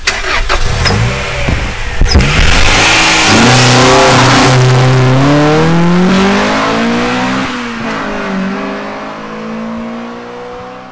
Sons de moteurs honda - Engine sounds honda - bruit V8 V10 honda
INTEGRA R (drive_away).wav